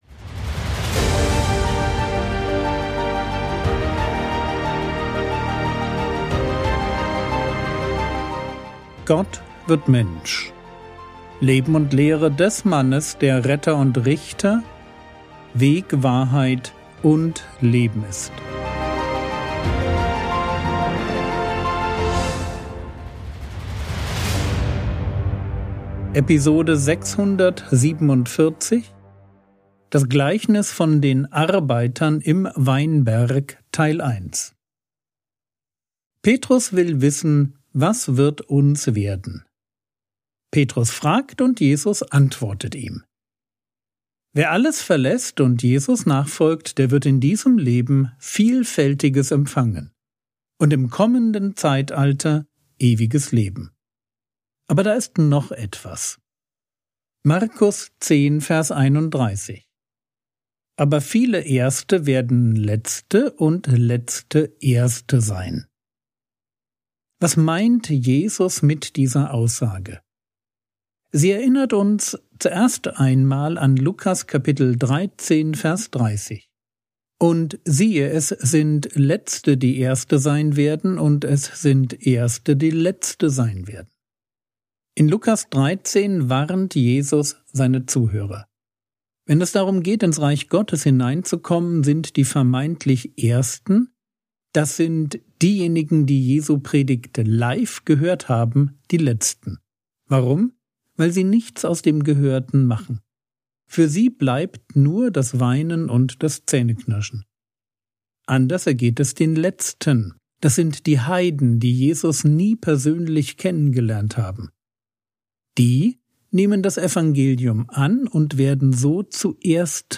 Episode 647 | Jesu Leben und Lehre ~ Frogwords Mini-Predigt Podcast